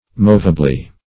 movably - definition of movably - synonyms, pronunciation, spelling from Free Dictionary Search Result for " movably" : The Collaborative International Dictionary of English v.0.48: Movably \Mov"a*bly\, adv. In a movable manner or condition.